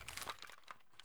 mp153_reload_out.ogg